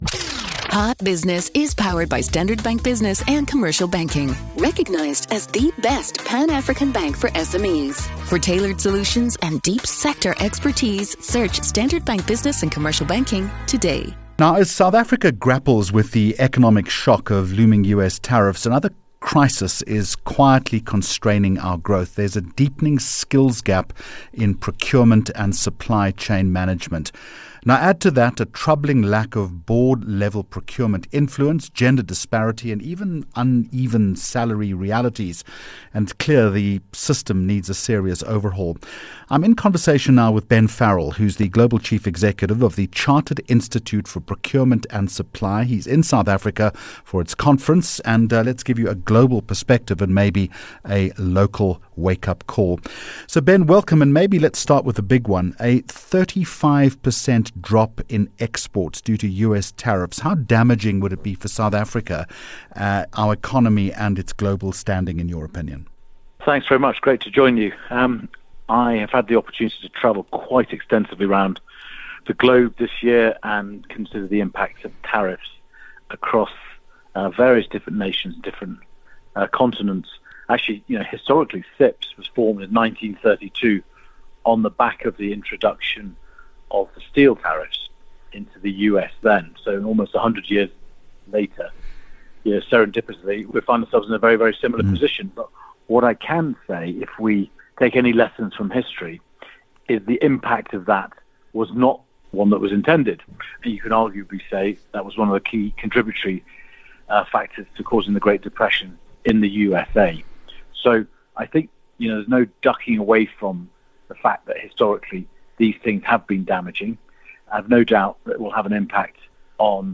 6 Aug Hot Business Interview